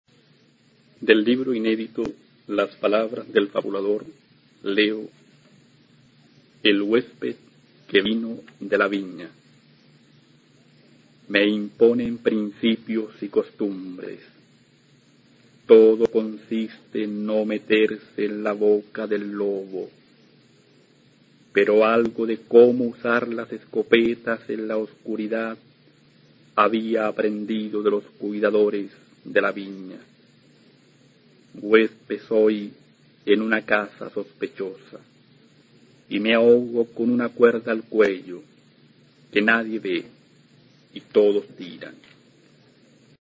Poema